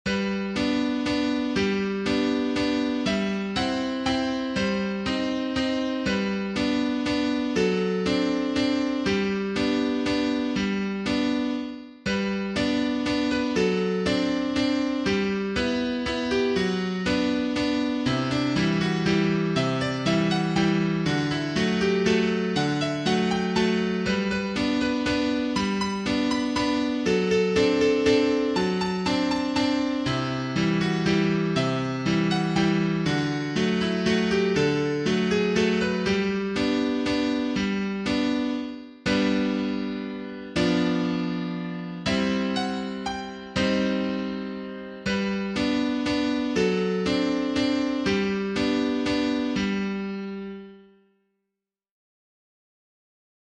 手遊び歌